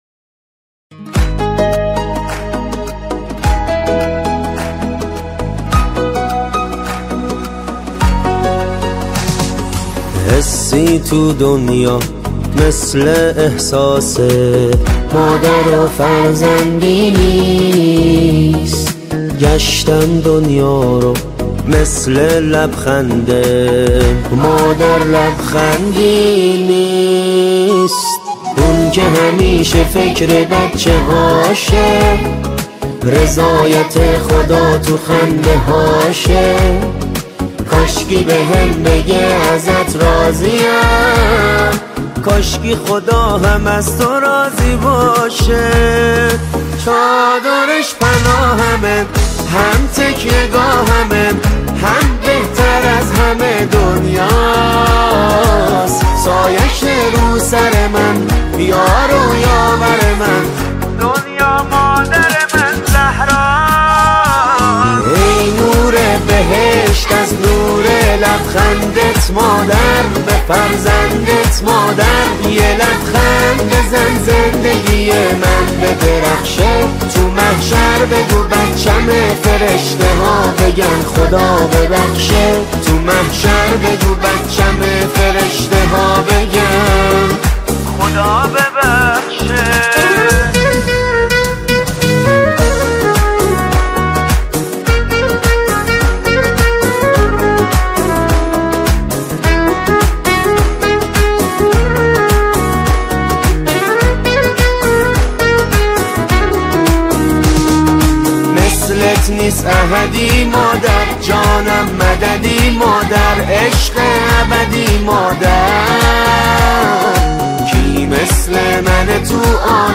با نوای دلنشین
اثری عاطفی، معنوی و تکریم‌آمیز است